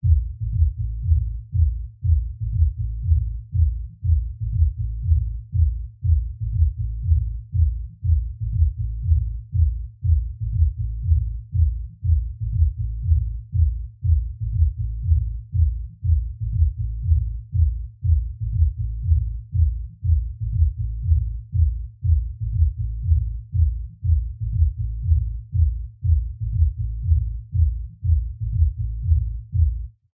Музыка за стеной или сверху, грохочут басы и ударные, танцевальные ритмы пробиваются сквозь стены